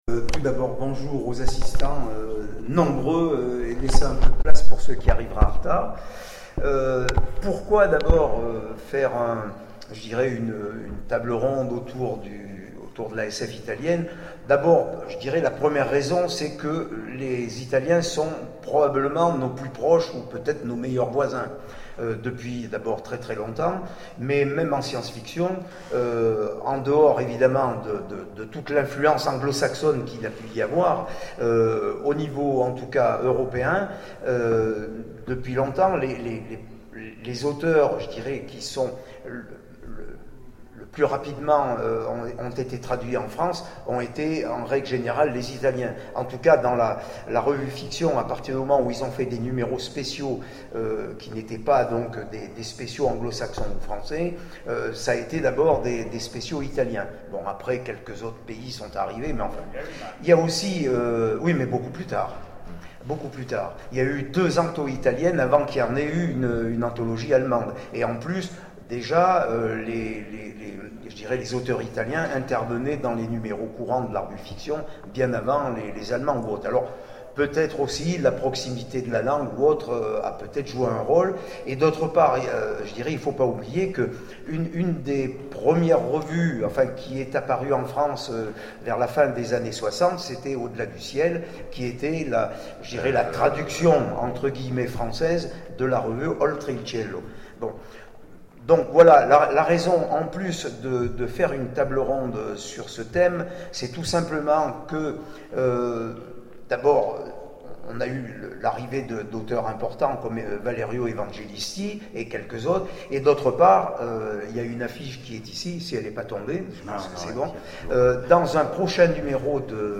Convention SF d'Aubenas - La Science-fiction italienne
Conférence